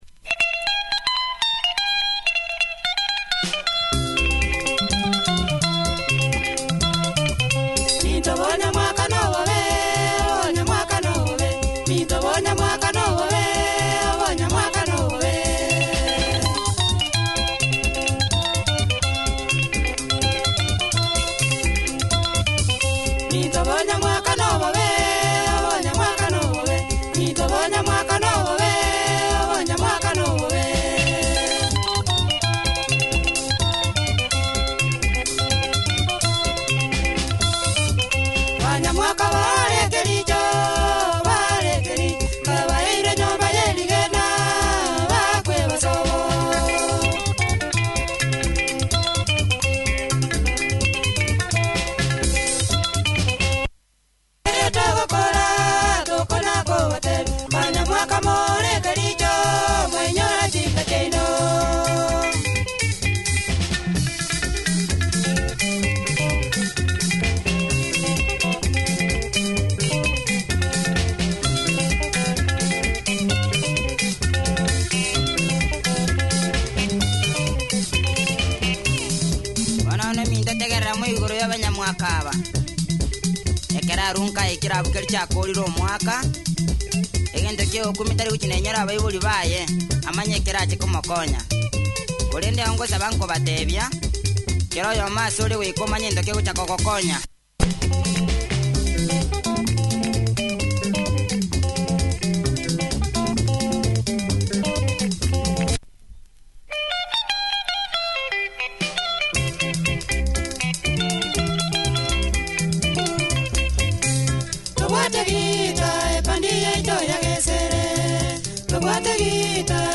Tight Kisii Benga, punchy production good tempo, loud too.